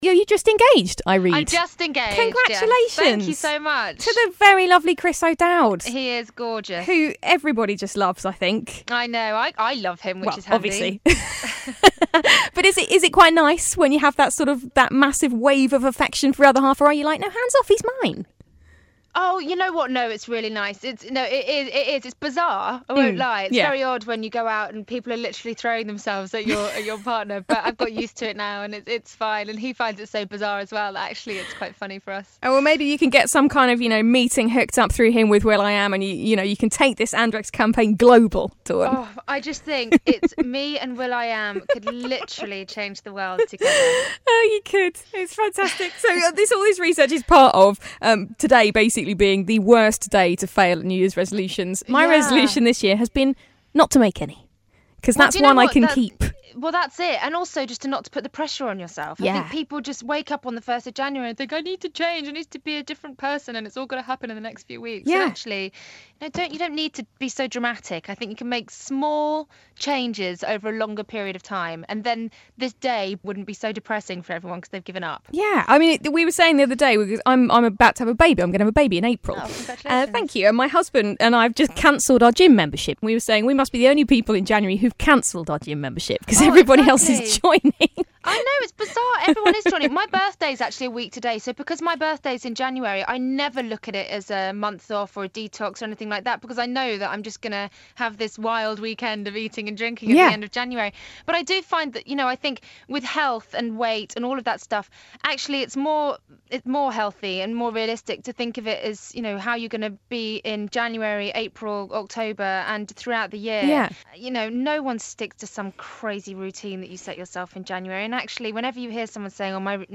Glide's Morning Glory Interview Dawn Porter ( part 2)